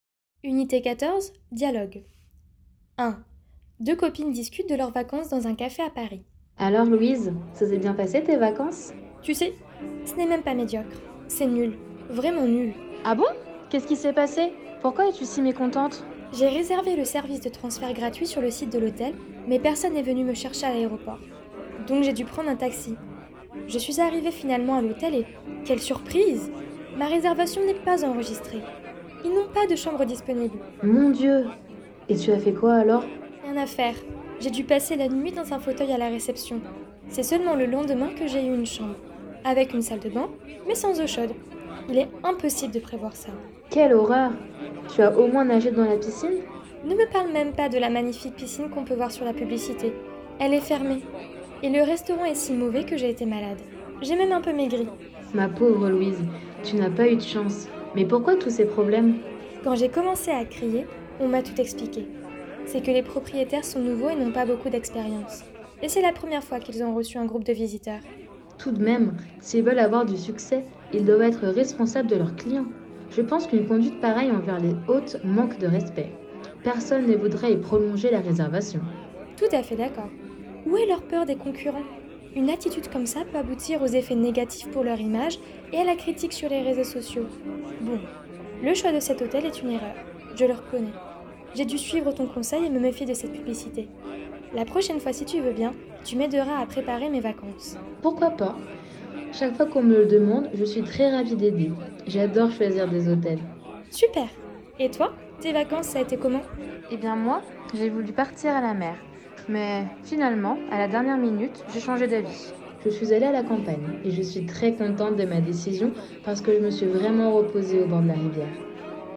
Dialogue 1 — Deux copines discutent de leurs vacances dans un café à Paris.
Unite-14-dialogue-1.mp3